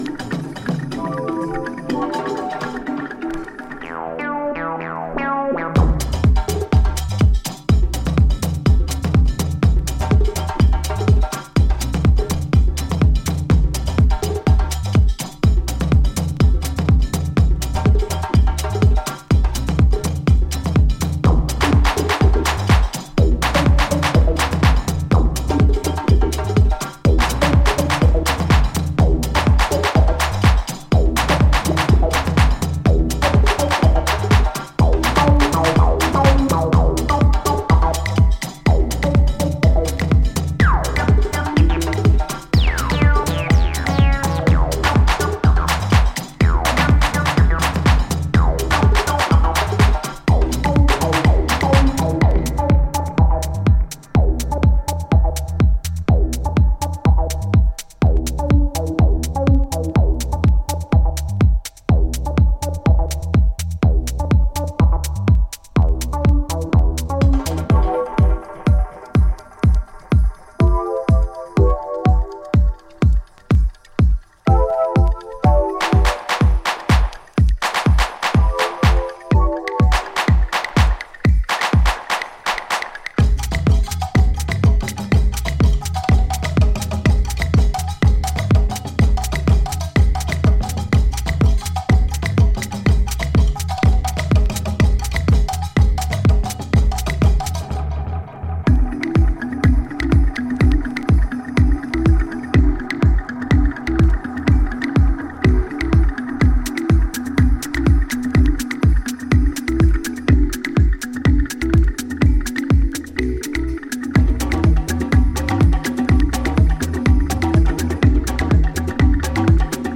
パーカッションの連打に差し込まれるベースラインで両手が上がりそうな